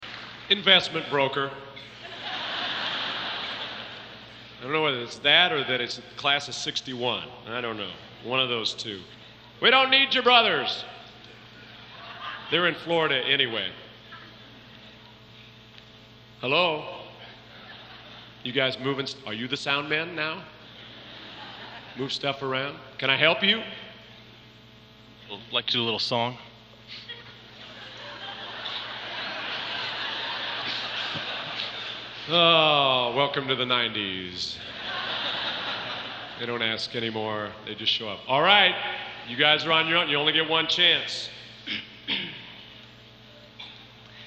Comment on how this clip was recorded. Collection: Centennial Celebration Concert 1993